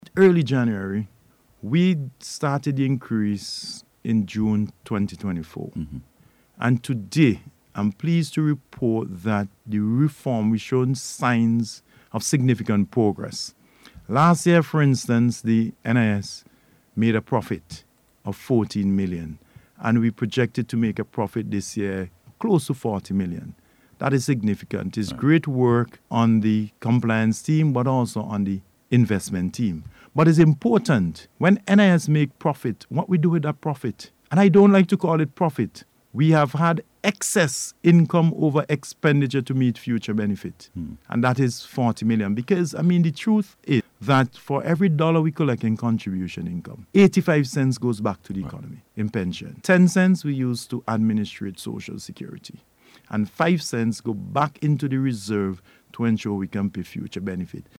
speaking on NBC Radio Thursday
NIS-DISCUSSION.mp3